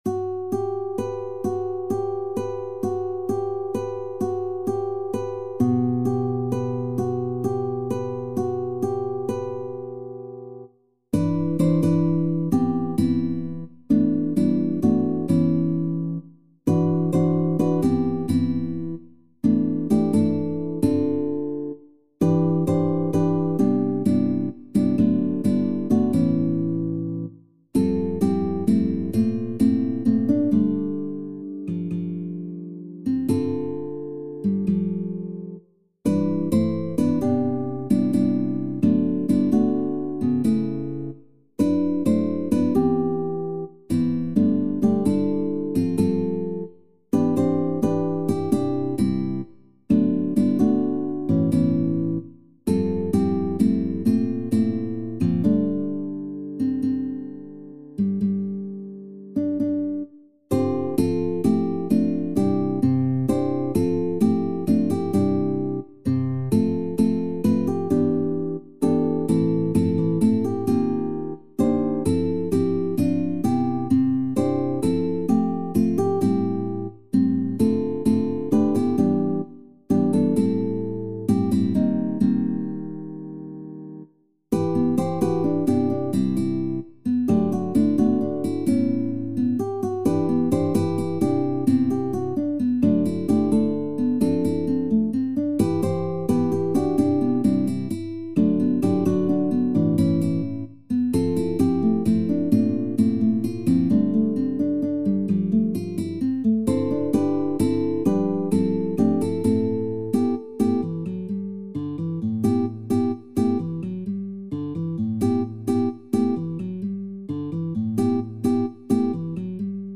SATB avec solo | TTBB avec solo | SSAB avec solo